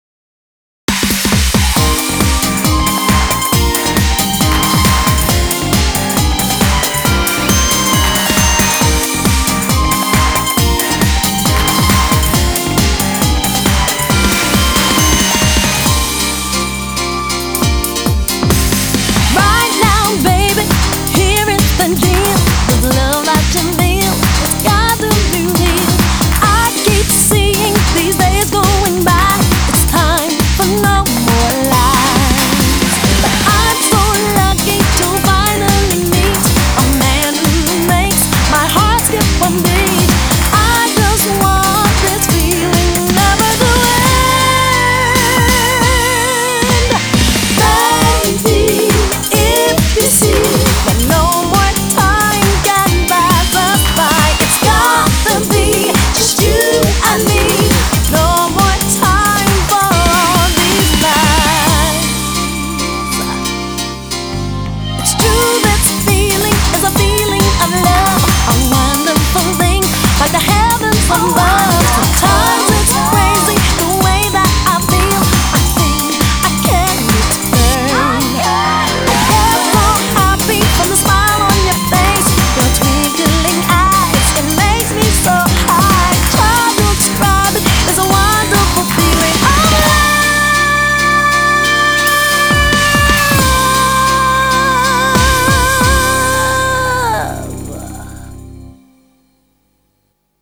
BPM136